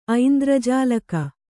♪ aindrajālaka